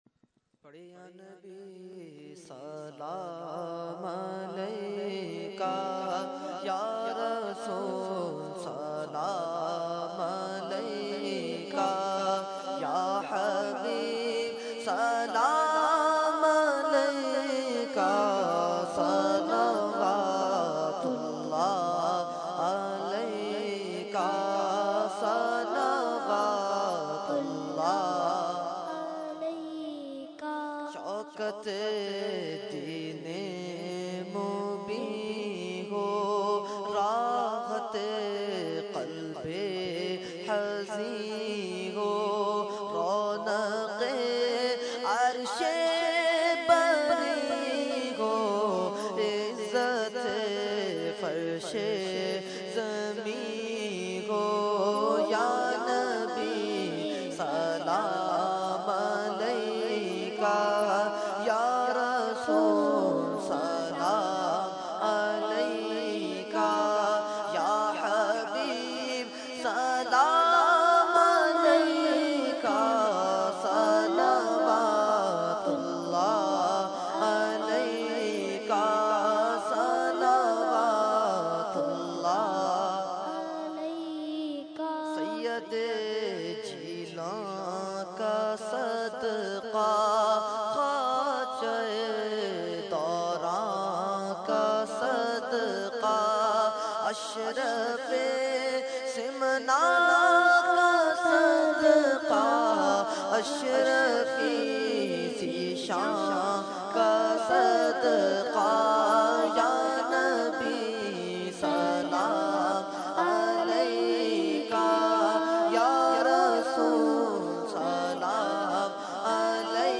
Category : Salam | Language : UrduEvent : Mehfil 11veen Nazimabad 22 February 2014